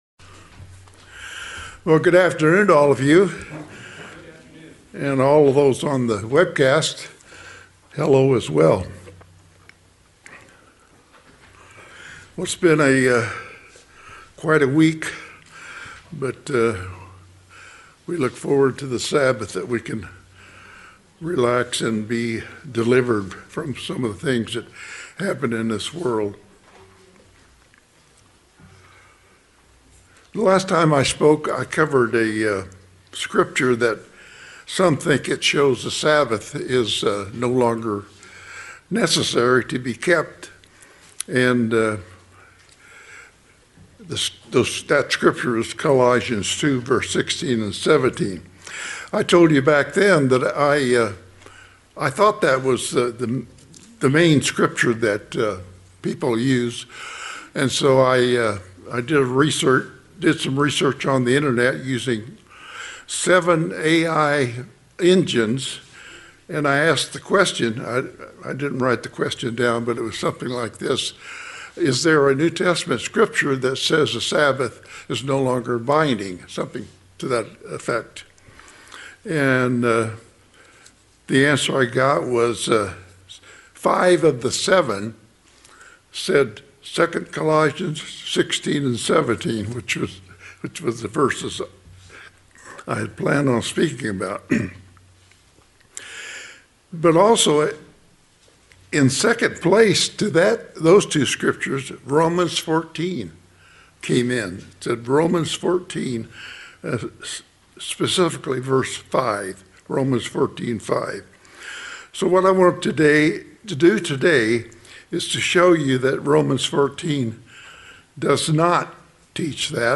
Given in San Diego, CA Redlands, CA Las Vegas, NV